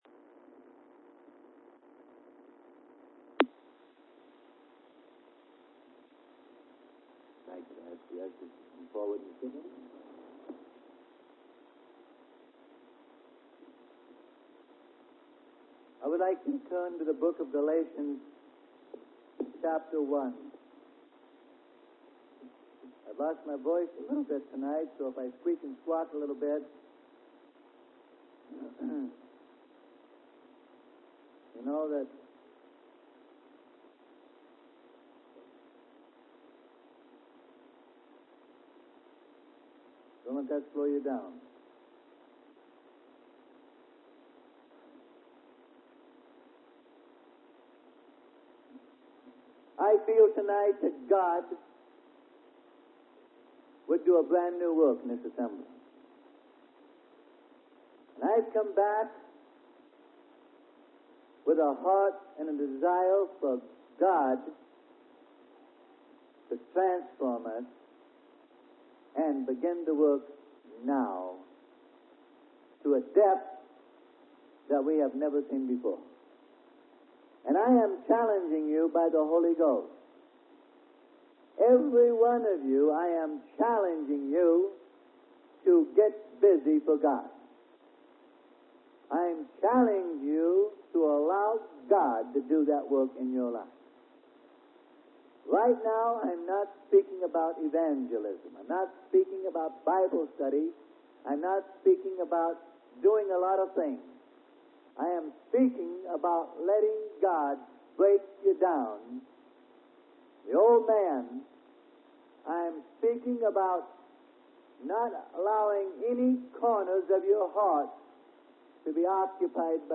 Sermon: Re-Examining Our Life In Christ: Revealing Christ - Part 1 Of 3.